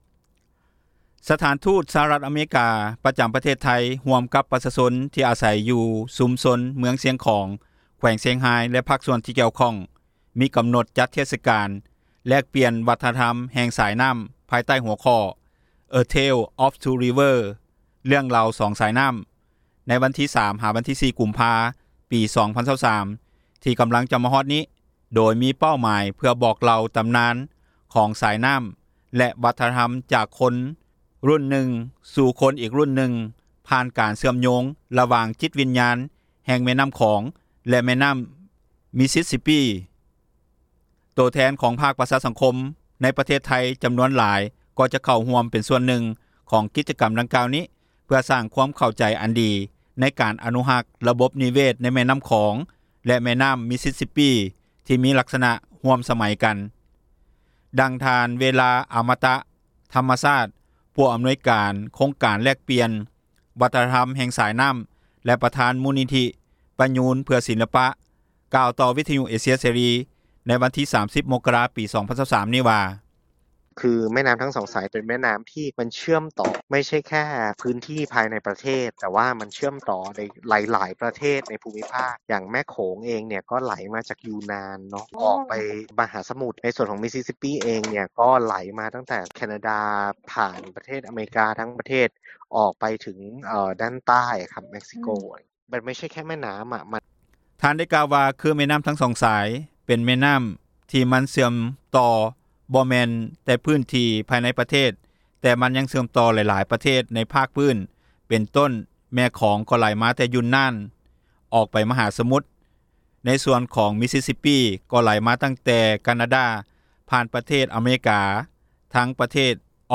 ດັ່ງຊາວບ້ານ ຢູ່ເມືອງຊຽງຂອງ ແຂວງຊຽງຮາຍ ທີ່ສົນໃຈເດີນທາງ ເຂົ້າໄປຮ່ວມກິຈກັມ ແລກປ່ຽນວັທນະທັມ ລະຫວ່າງແມ່ນໍ້າຂອງ ແລະນໍ້າມິສຊິສຊິບປີ້ ທ່ານນຶ່ງກ່າວວ່າ: